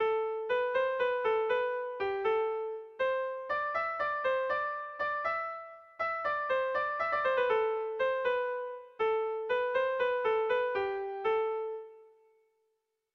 Erlijiozkoa
ABDA